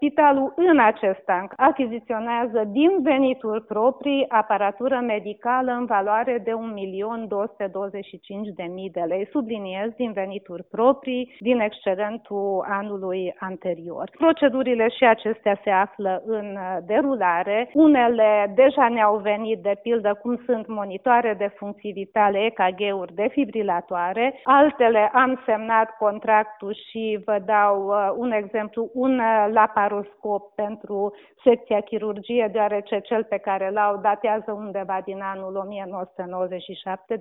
a acordat astăzi un interviu pentru Radio Tg. Mureş